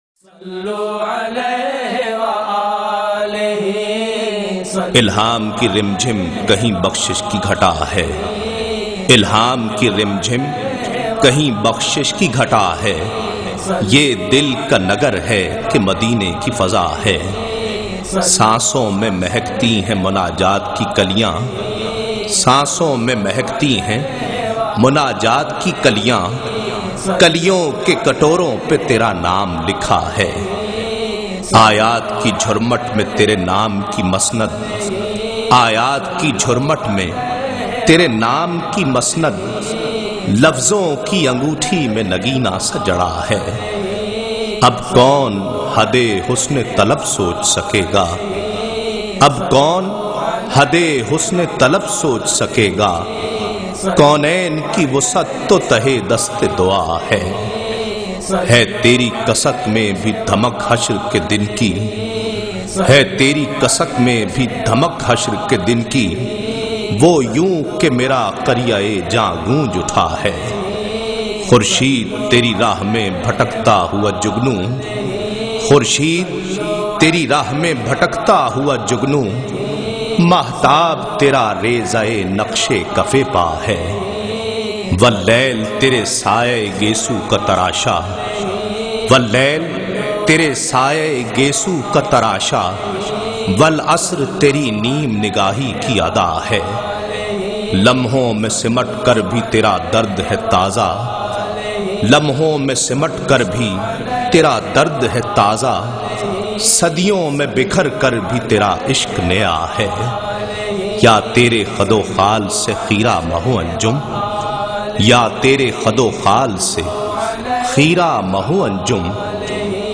URDU NAAT
in a Heart-Touching Voice